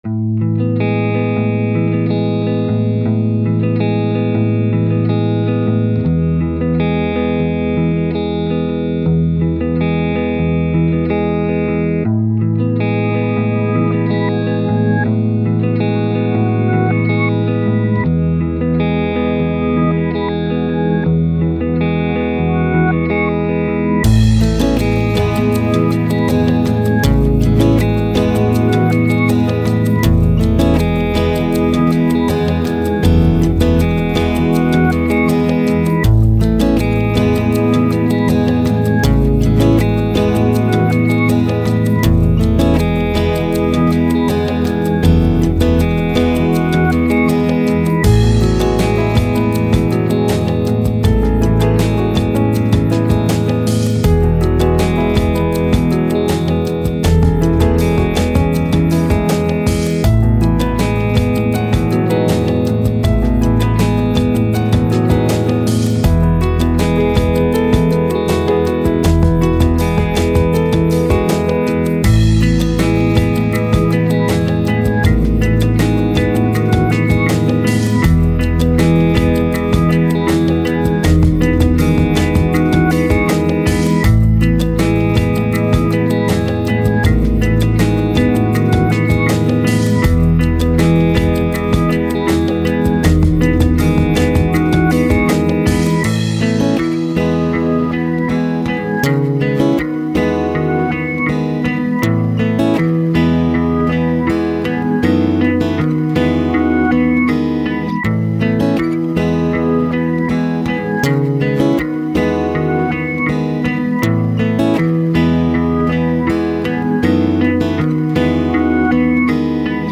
Downtempo, Ambient, Soundtrack, Reverse